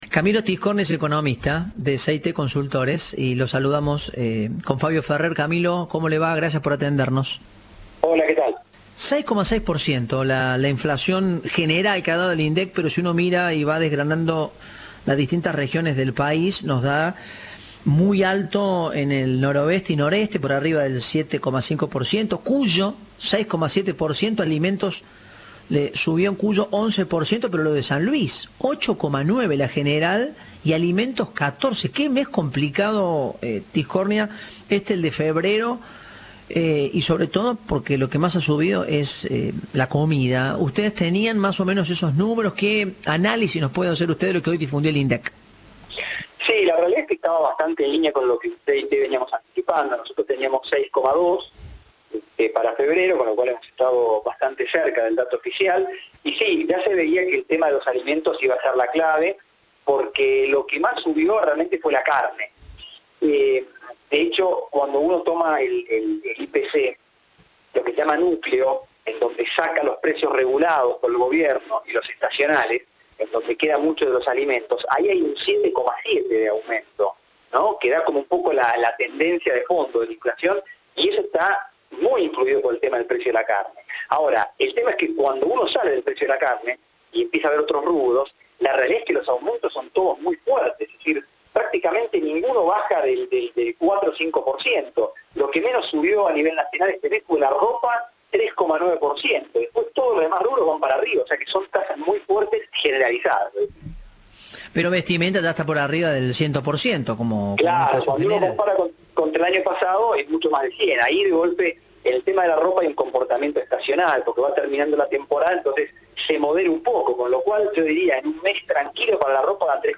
Entrevista de "Informados, al Regreso".